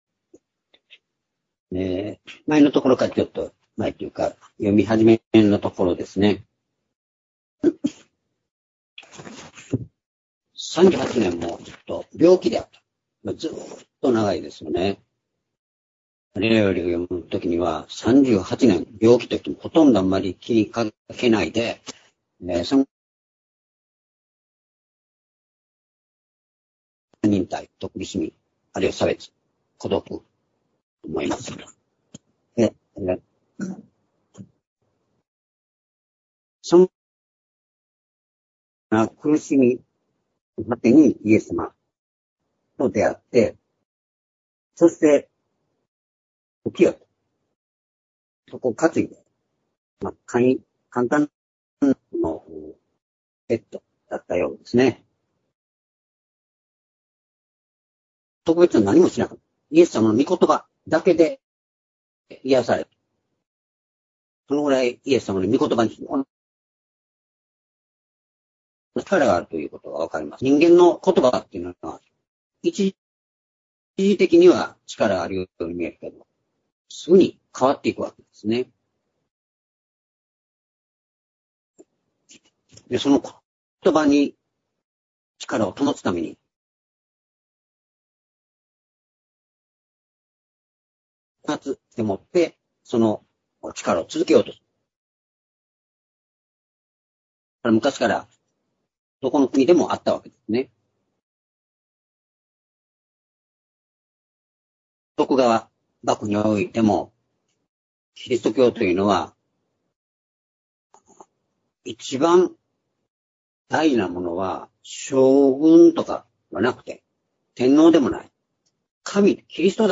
主日礼拝日時 2022年6月26日(主日) 聖書講話箇所 「働き続ける主」 ヨハネ4章10～17節 ※視聴できない場合は をクリックしてください。